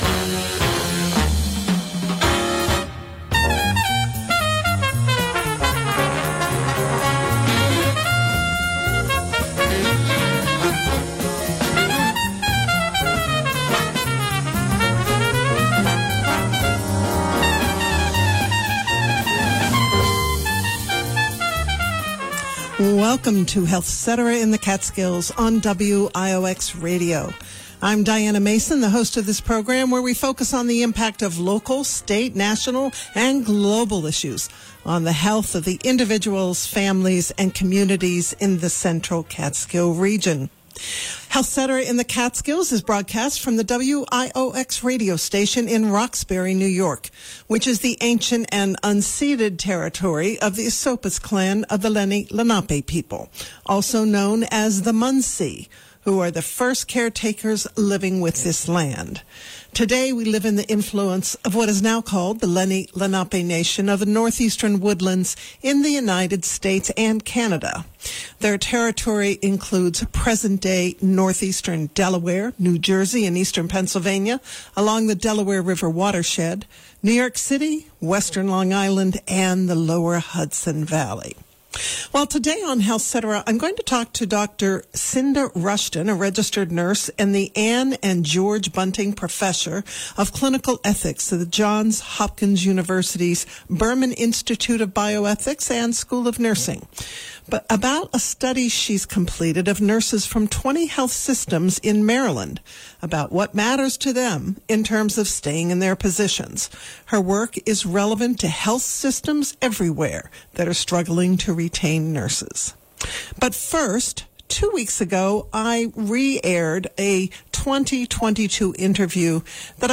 This interview first aired on HealthCetera in the Catskills on WIOX Radio on December 11, 2024.